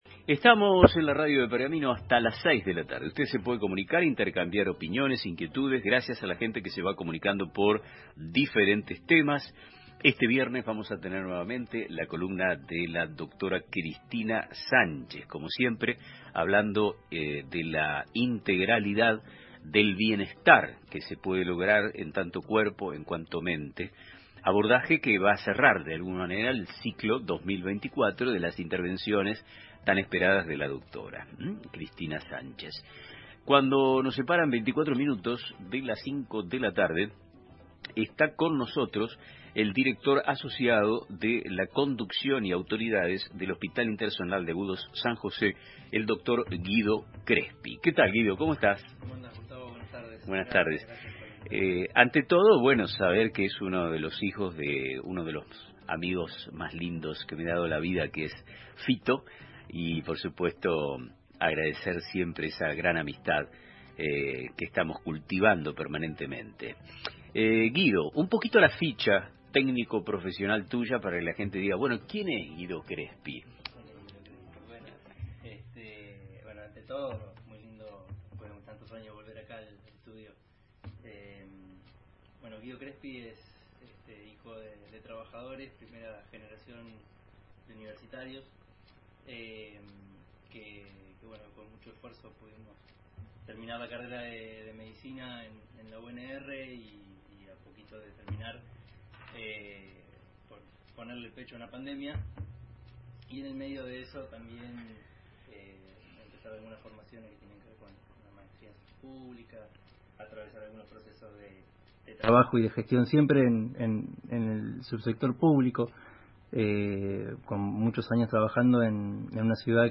En una entrevista exclusiva para el programa Nuestro Tiempo